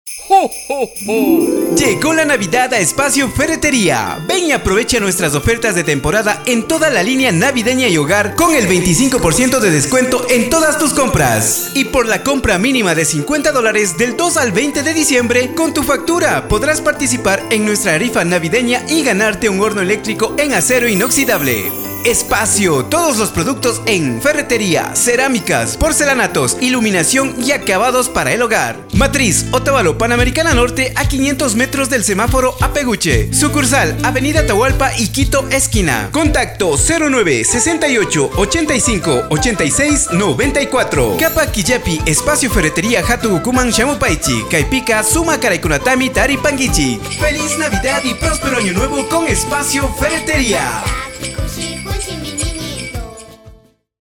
Grabación y Producción de cuñas | Radio Ilumán
En Radio Ilumán te ofrecemos el servicio profesional de grabación de cuñas radiales en dos idiomas: kichwa y castellano, con locutores y locutoras que comunican con autenticidad, claridad y cercanía a la audiencia.